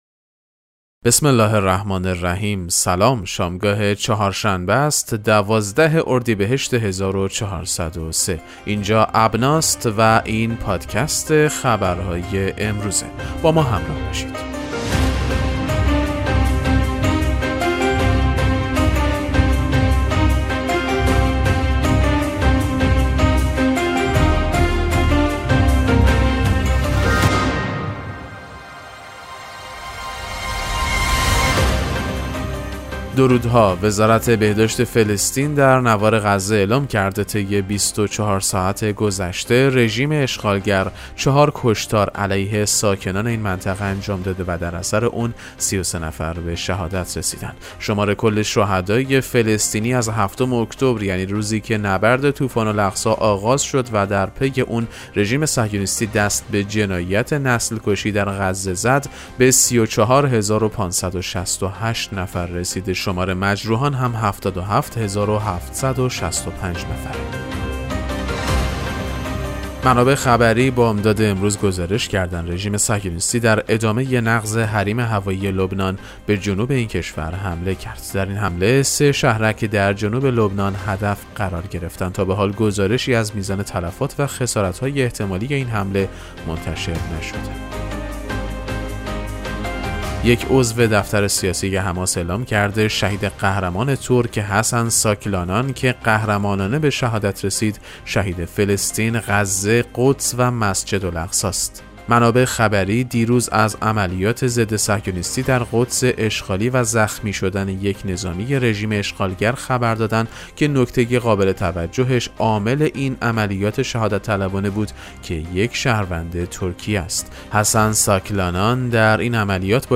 پادکست مهم‌ترین اخبار ابنا فارسی ــ 12 اردیبهشت 1403